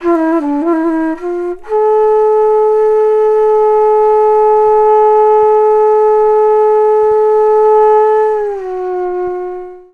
FLUTE-B08 -L.wav